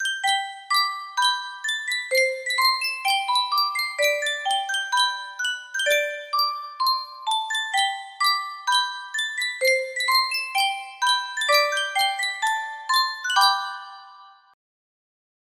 Sankyo Music Box - My Old Kentucky Home DD music box melody
Sankyo Music Box - My Old Kentucky Home DD
Full range 60